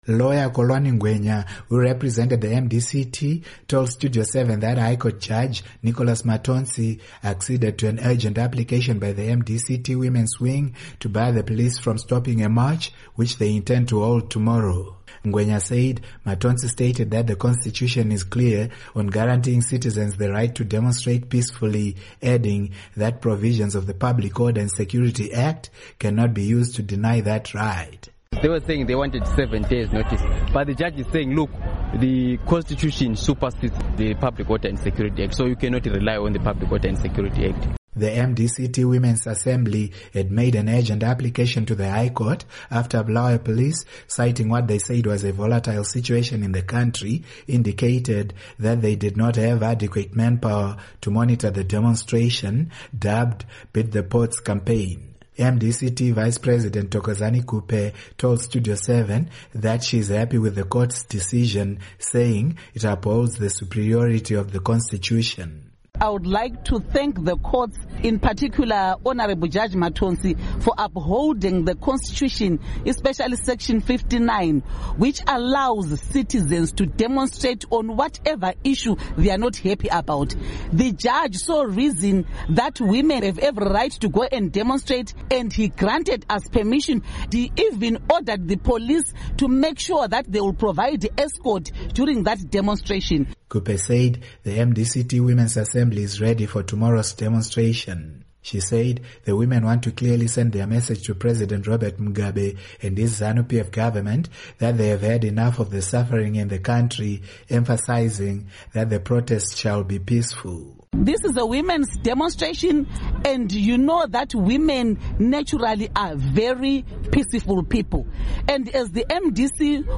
BULAWAYO —